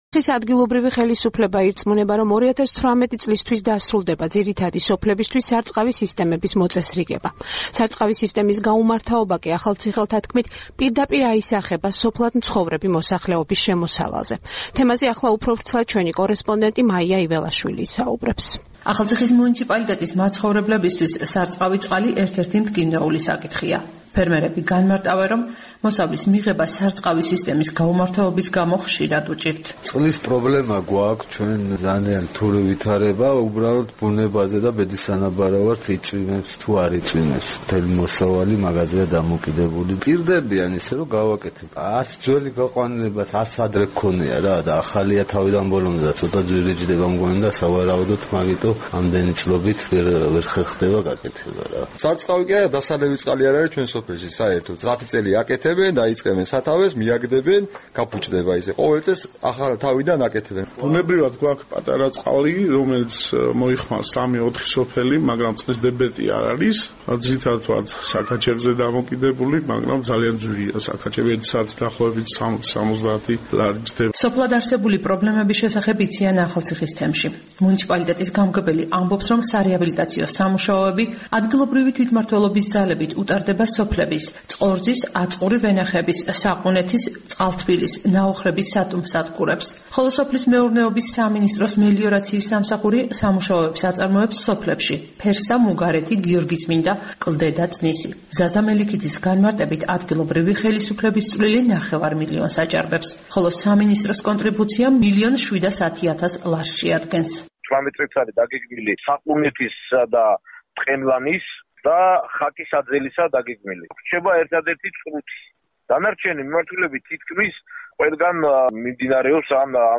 ზაფხულის მოახლოებასთან ერთად სოფლის მოსახლეობისთვის ერთ-ერთი ყველაზე მთავარი პრობლემა იჩენს თავს. სარწყავი სისტემის გაუმართაობა და უწყლოდ დარჩენილი მოსავალი პირდაპირ აისახება სოფლად მცხოვრები მოქალაქეების შემოსავალზე. ახალციხის მუნიციპალიტეტის მცხოვრებლებისთვის სარწყავი წყალი ერთ-ერთი მტკივნეული საკითხია. ფერმერები განმარტავენ, რომ მოსავლის მიღება სარწყავი სისტემის გაუმართაობის გამო ხშირად უჭირთ.